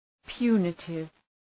Προφορά
{‘pju:nıtıv}